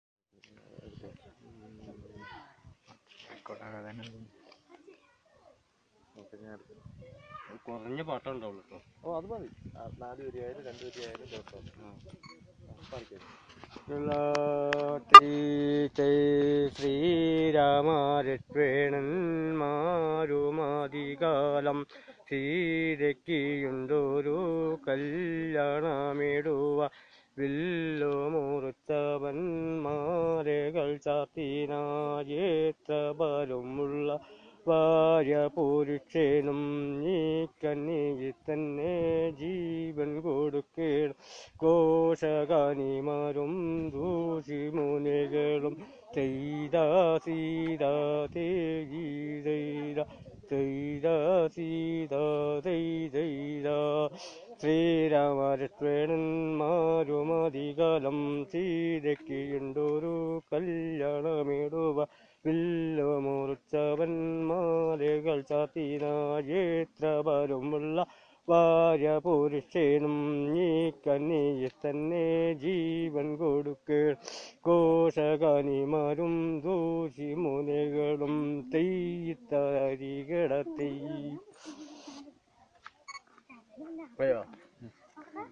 Performance of folk song